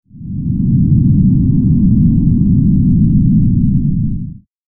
環境音 | 無料 BGM・効果音のフリー音源素材 | Springin’ Sound Stock
地響き3短.mp3